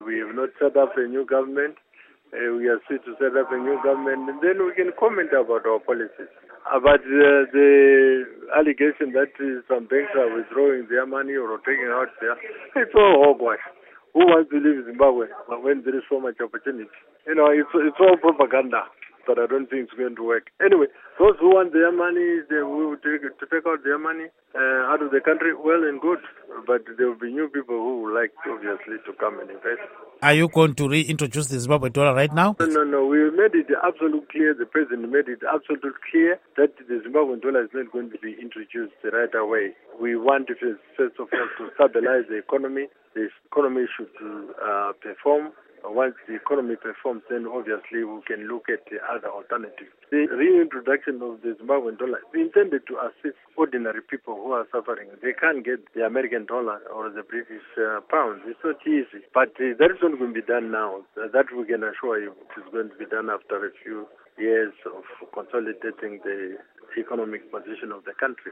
Interview With Roy Bennet